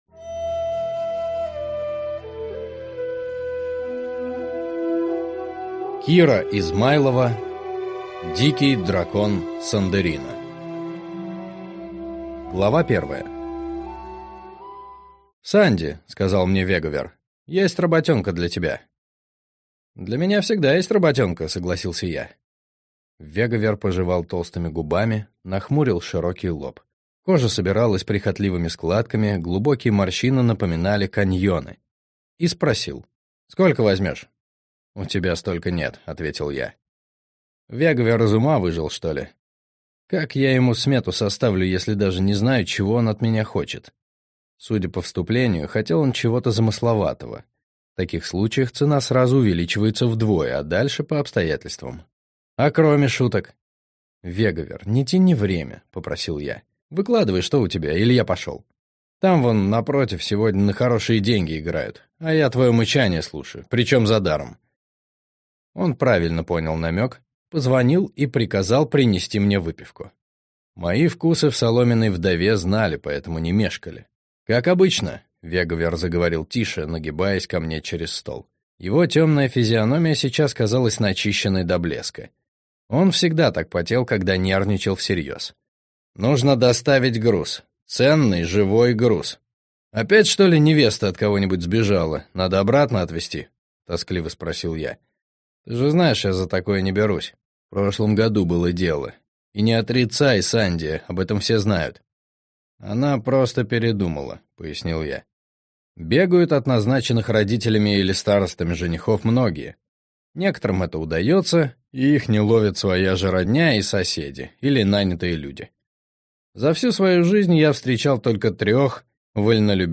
Аудиокнига Дикий дракон Сандеррина | Библиотека аудиокниг